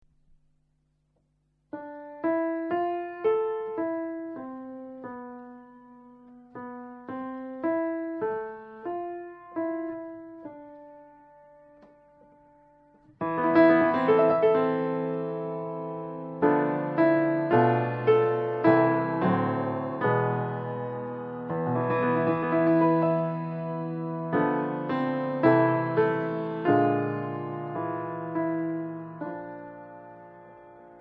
piano
fluit